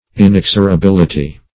Inexorability \In*ex`o*ra*bil"i*ty\, n. [L. inexorabilitas: cf.